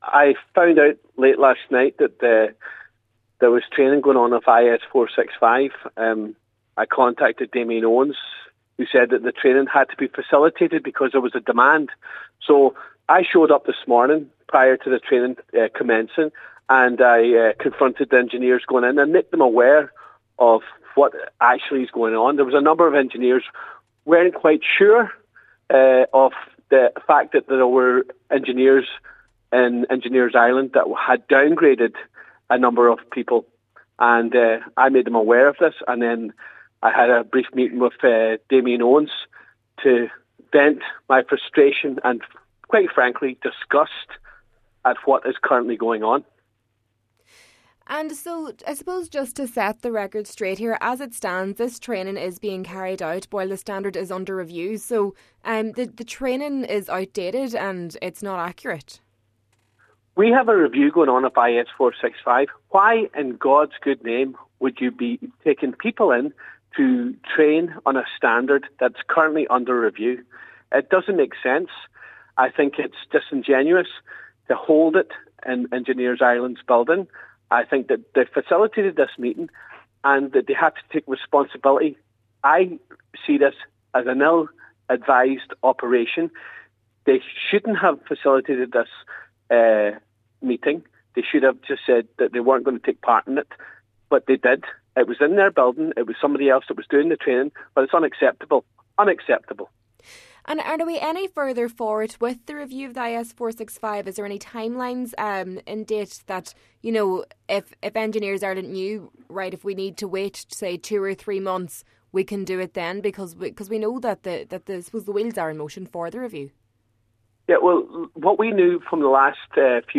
Deputy Charles Ward said he was disgusted when he heard that more training was being held on the outdated standard: